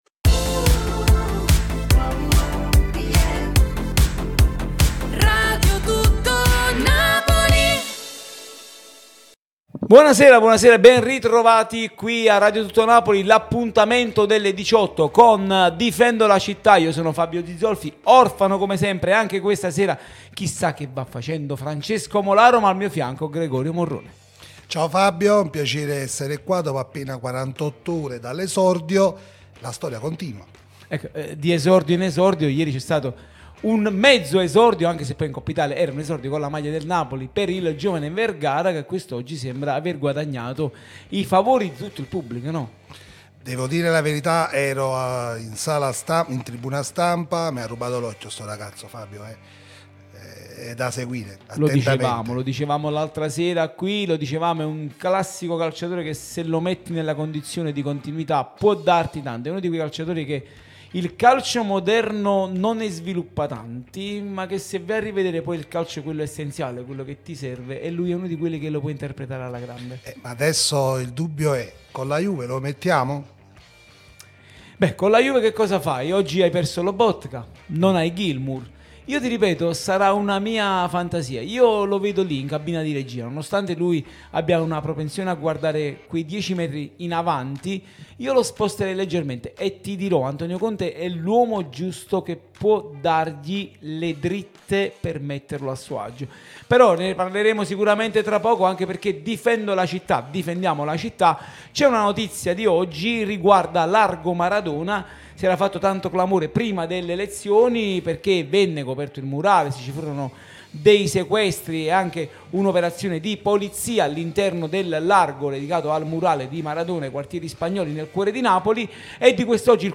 Ecco il motivo della protesta di oggi”. Di seguito il podcast completo